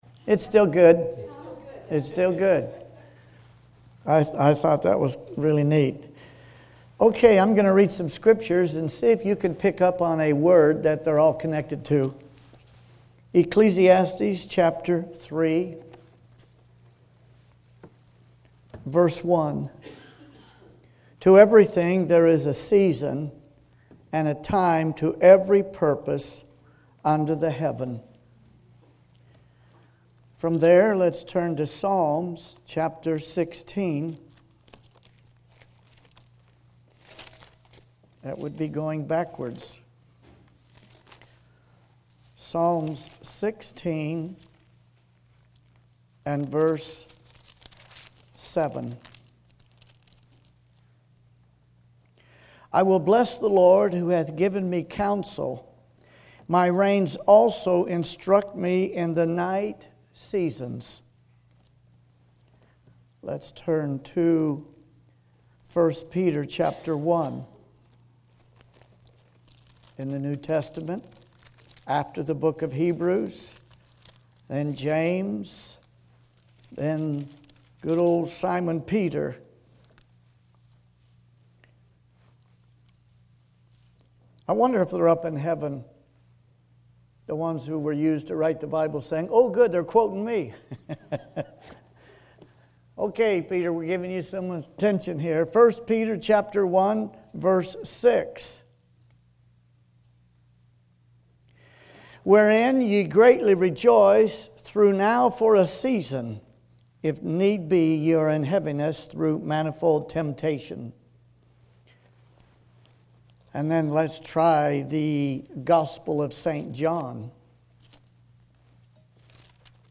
Evening Sermons